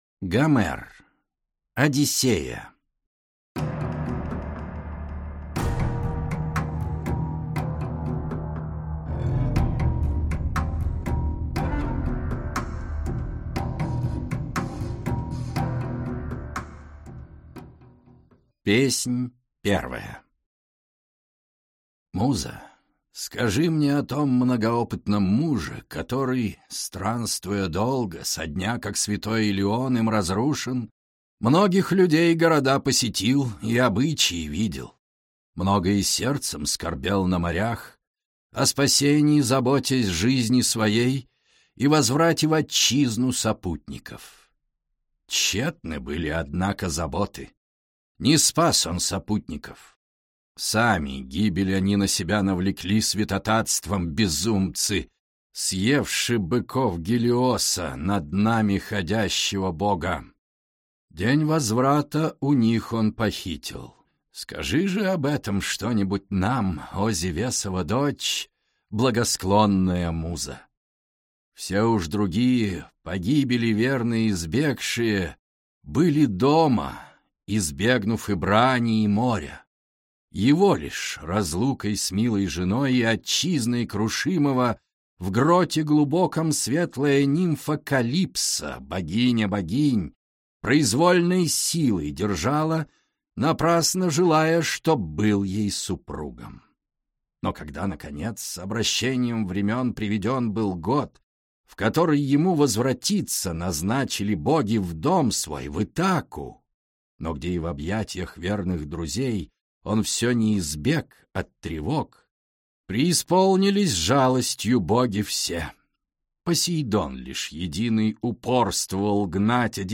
Аудиокнига Одиссея | Библиотека аудиокниг